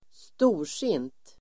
Uttal: [²st'o:r_sin:t]